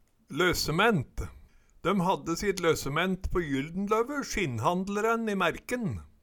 løssement - Numedalsmål (en-US)